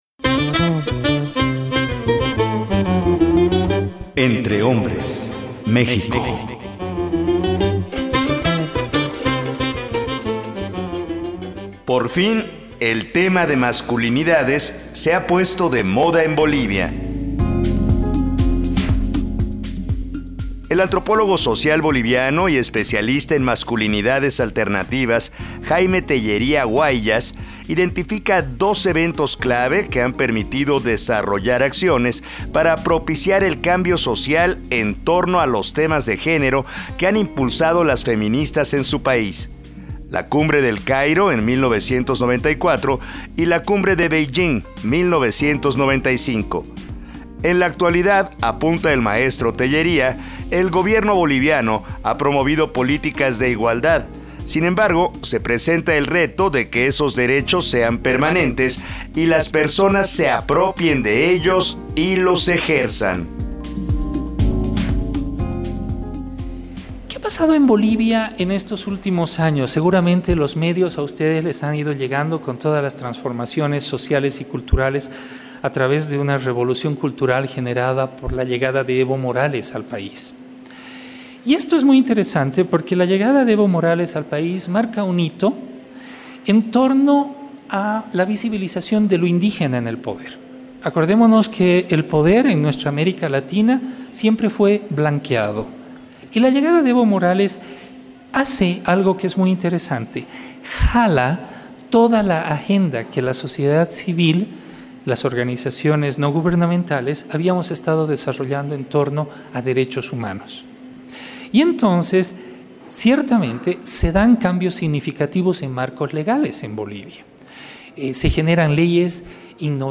Género y Masculinidades en Bolivia. Conferencia Magistral del Mtro.